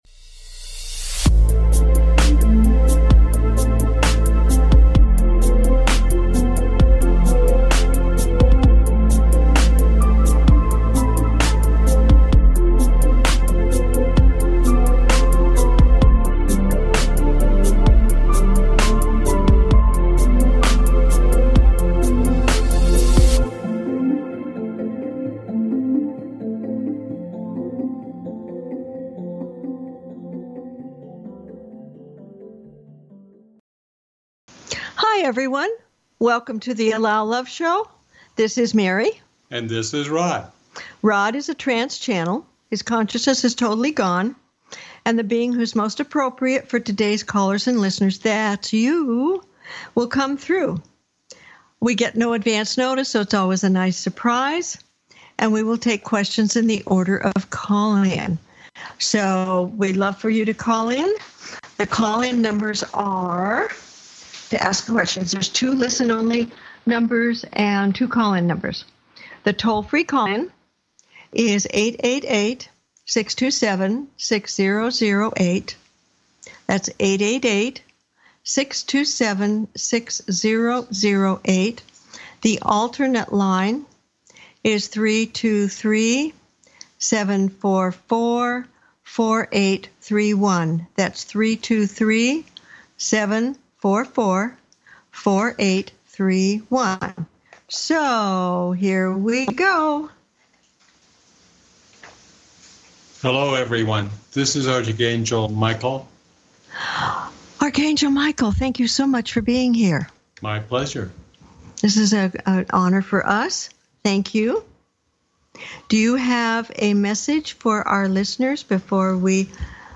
Talk Show Episode
Their purpose is to provide answers to callers’ questions and to facilitate advice as callers request.